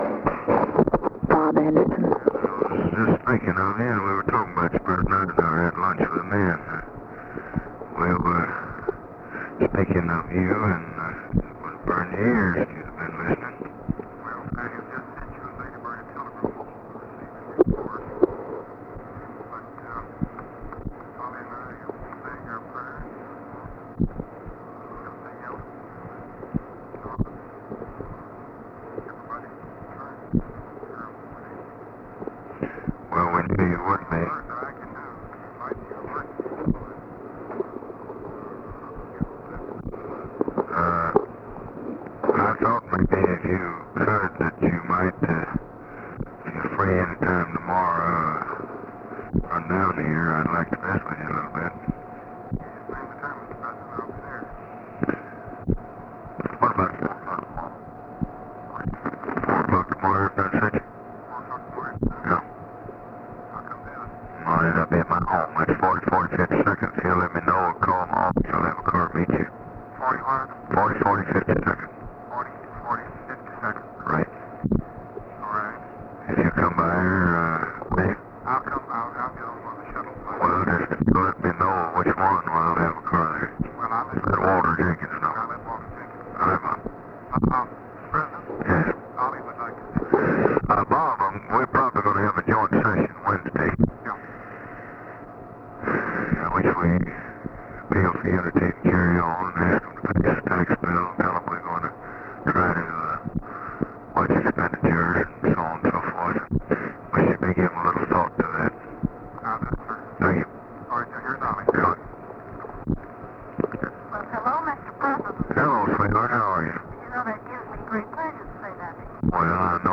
Conversation with ROBERT ANDERSON, November 23, 1963
Secret White House Tapes